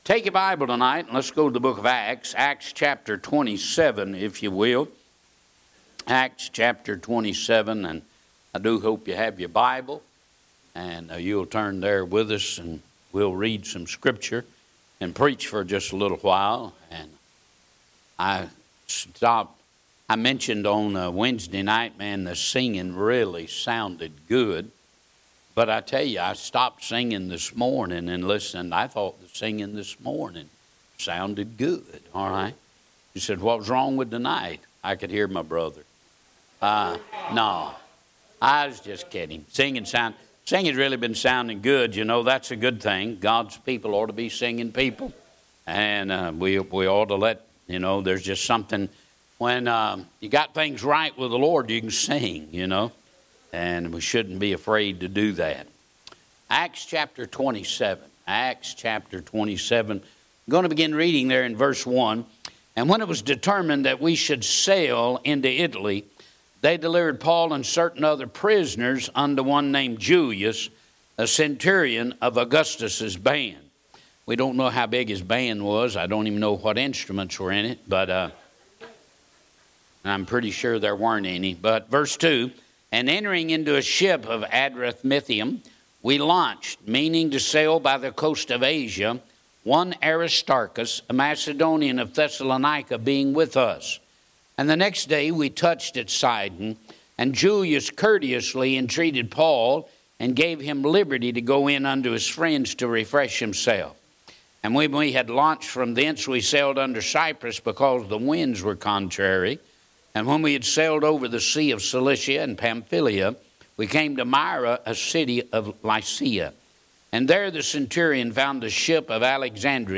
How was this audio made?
Sunday Evening